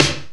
EMX SNR 1.wav